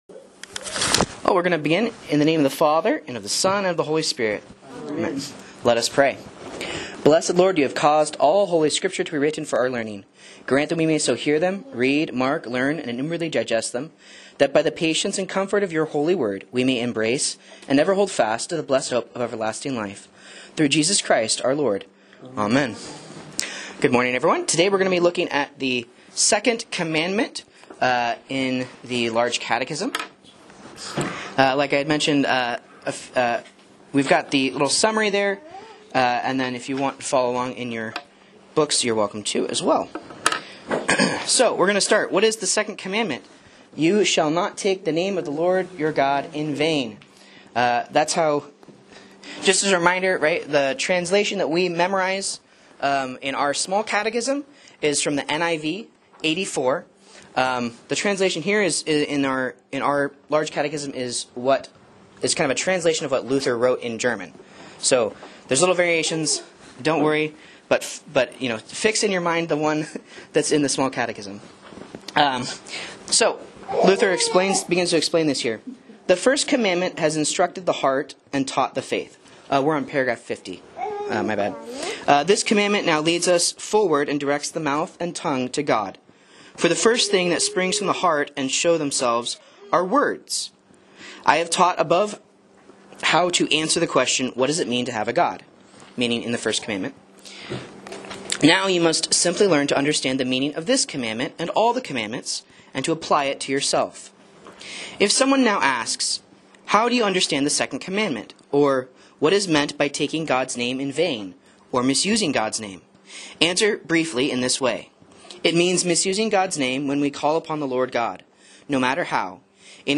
Sermons and Lessons from Faith Lutheran Church, Rogue River, OR